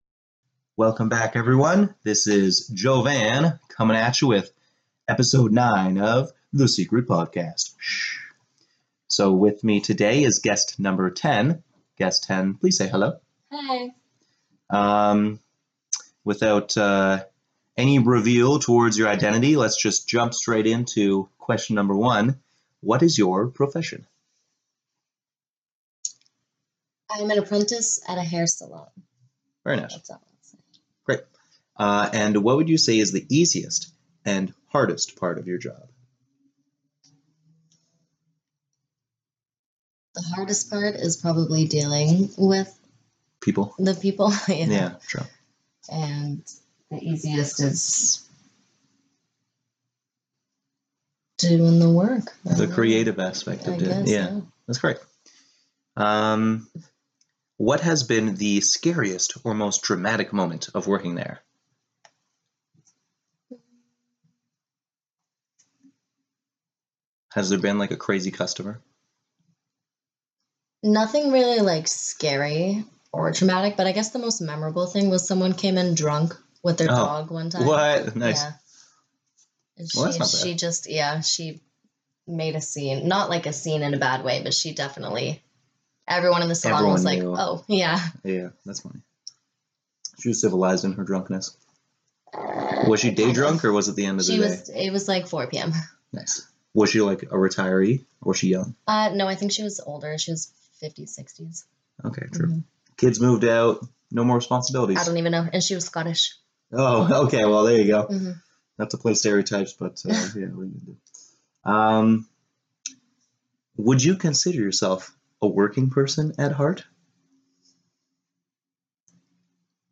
She is short spoken, but always passionate.